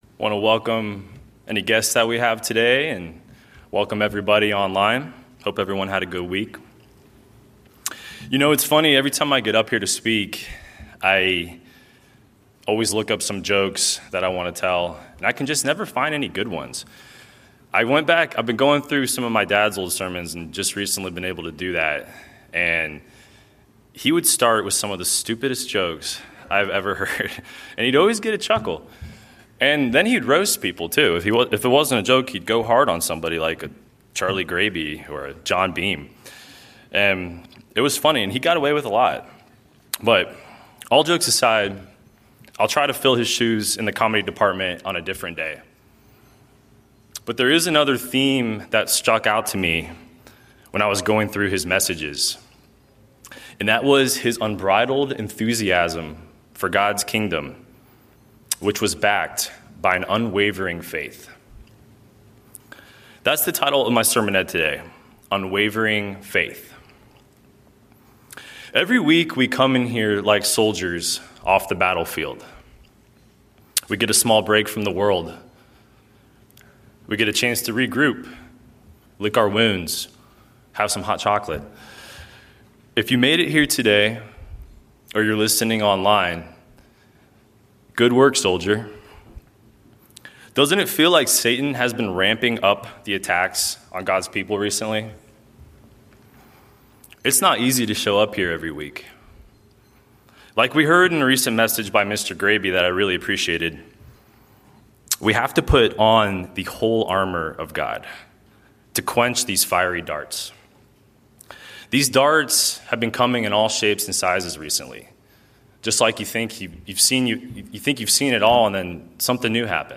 Given in Atlanta, GA